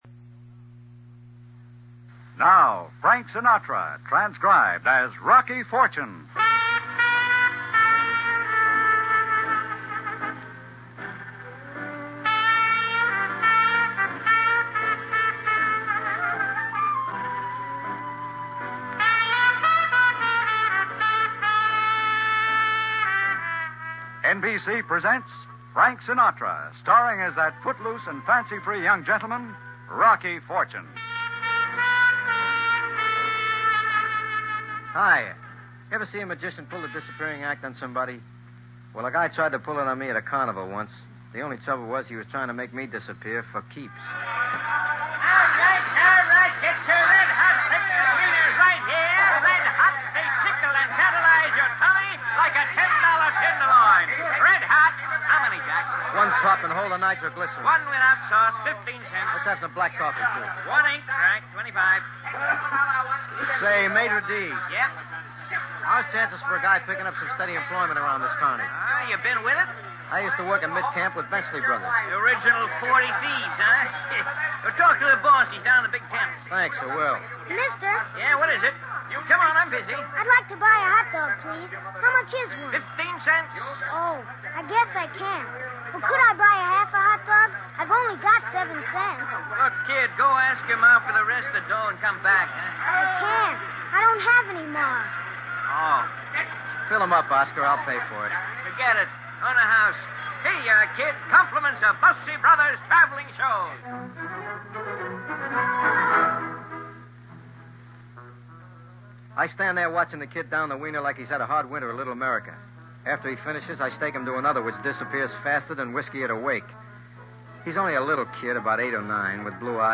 Rocky Fortune, Starring Frank Sinatra